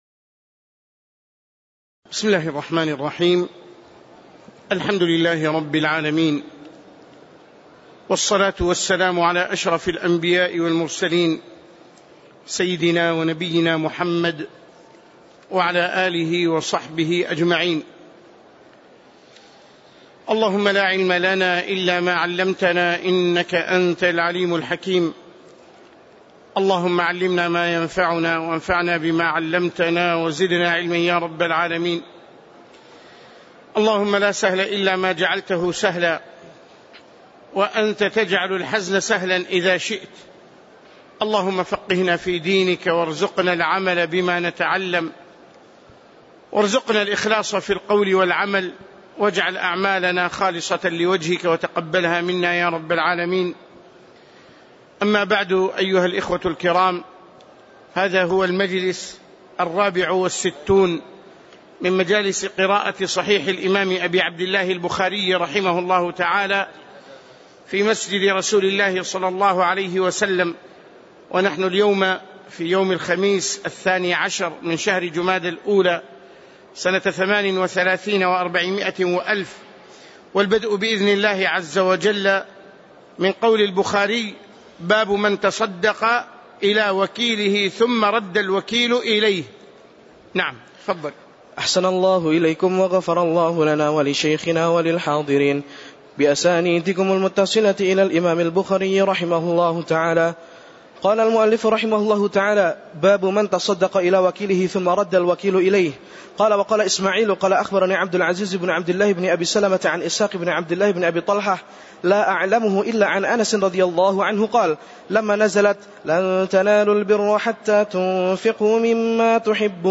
تاريخ النشر ١٢ جمادى الأولى ١٤٣٨ هـ المكان: المسجد النبوي الشيخ